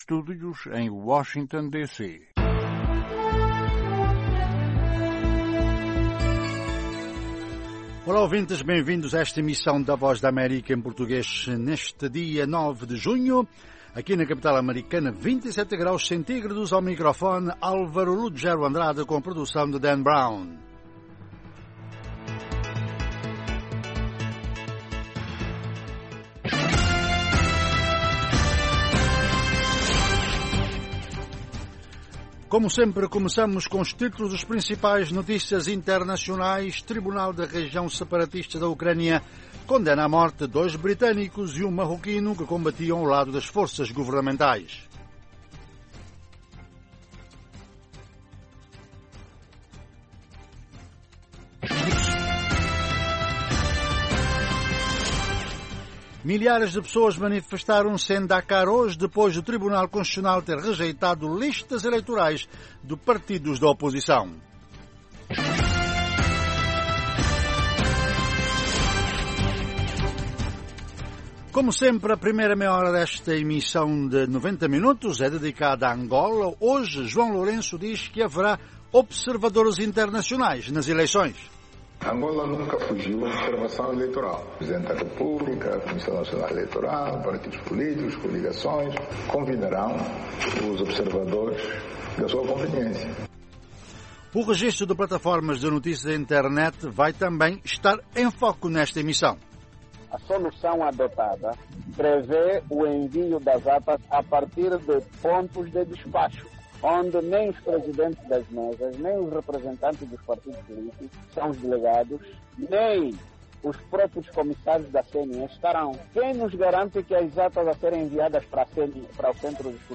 Emissão Vespertina orientada aos países Lusófonos de África, sem esquecer Brasil e comunidades de língua portuguesa noutras partes do mundo. Oferece noticias, informação, análises, artes e entretenimento, saúde, questões em debate em África.